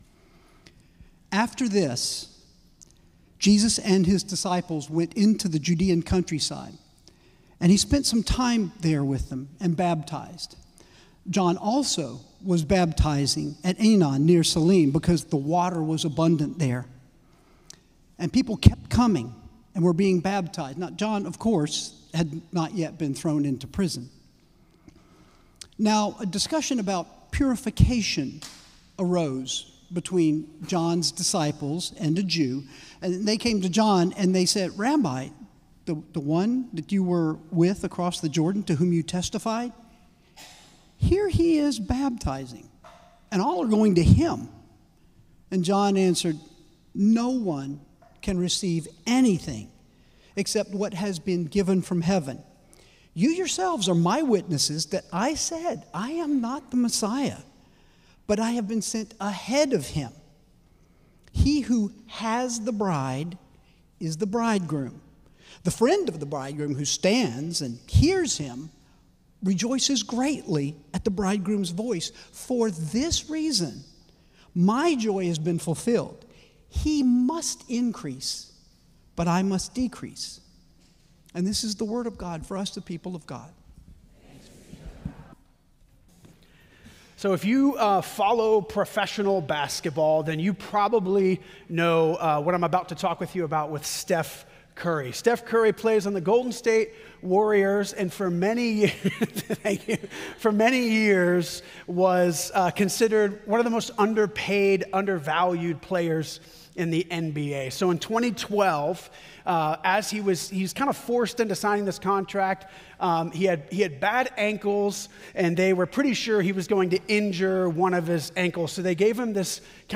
“humbled” Sermon Series, Week 1